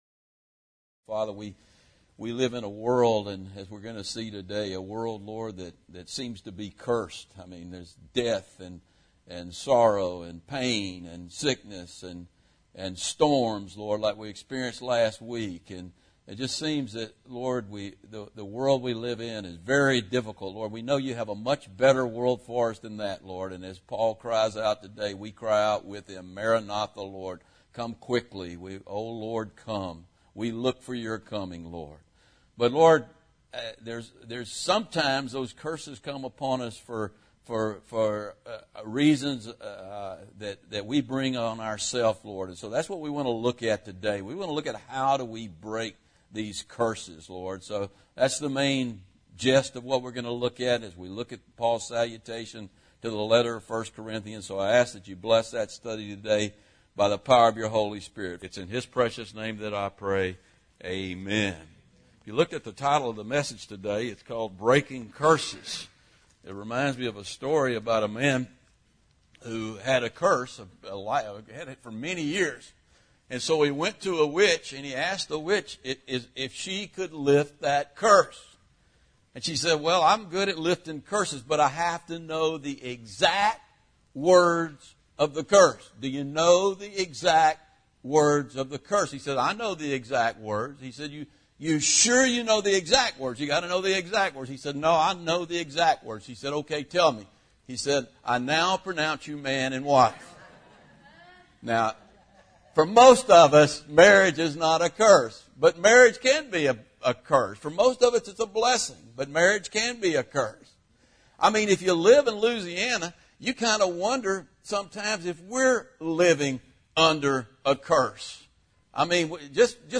1 Corinthians 16 – Breaking Curses (includes communion service) – Calvary Chapel Lafayette